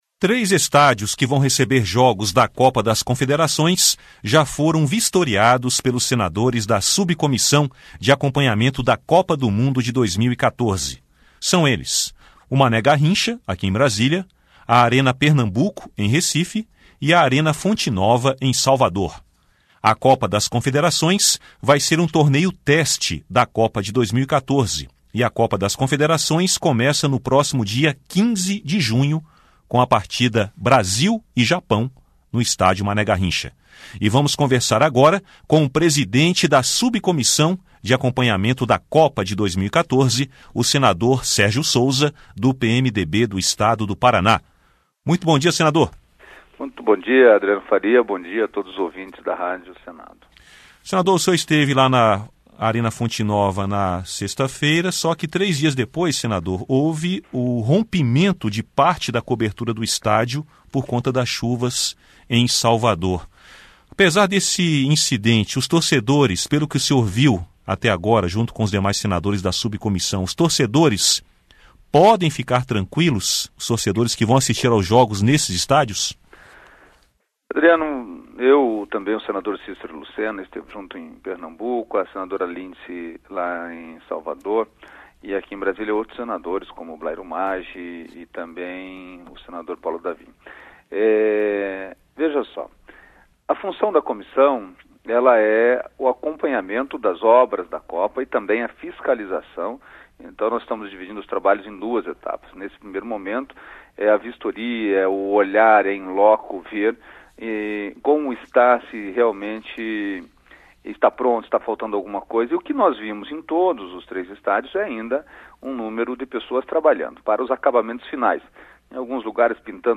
Entrevista com o senador Sérgio Souza (PMDB-PR), presidente da Subcomissão Temporária de Acompanhamento das Obras da Copa de 2014.